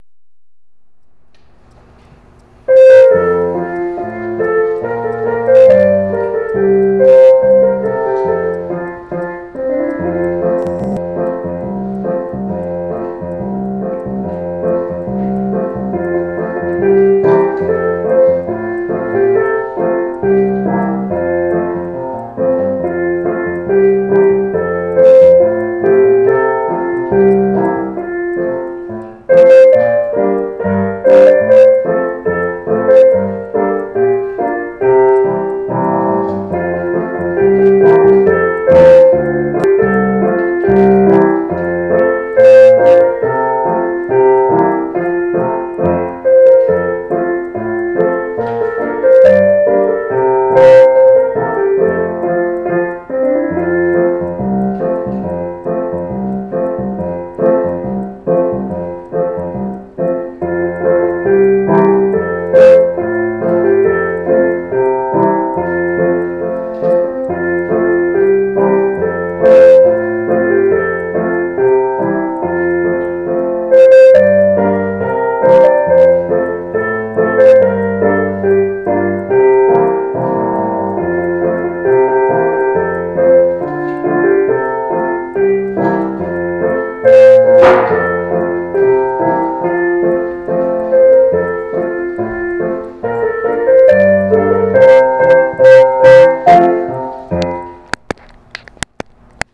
Reflections of a Lad at Sea (slow tempo, keyboard only)